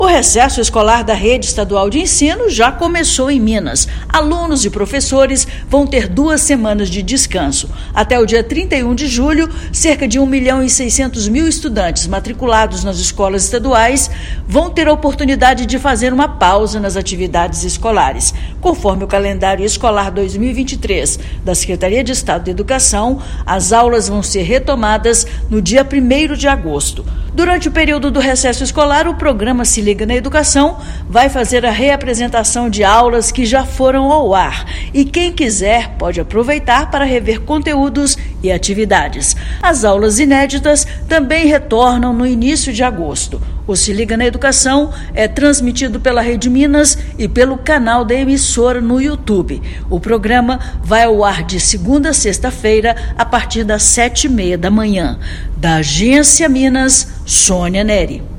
Recesso das escolas estaduais de Minas já começou e vai até 31/07. Ouça matéria de rádio.